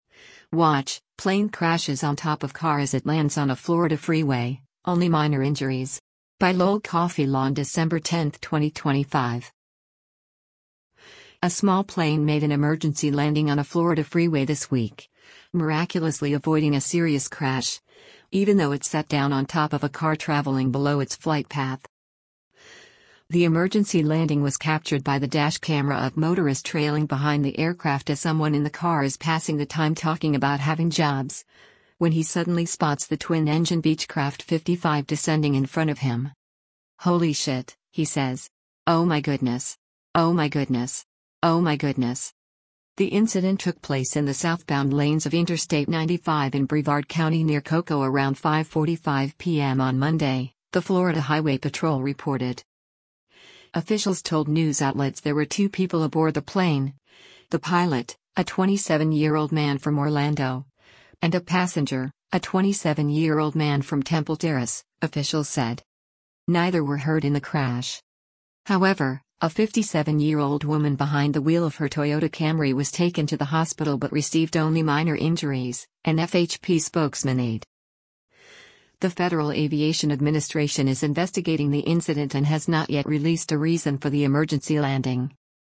The emergency landing was captured by the dash camera of motorist trailing behind the aircraft as someone in the car is passing the time talking about “having jobs,” when he suddenly spots the twin-engine Beechcraft 55 descending in front of him.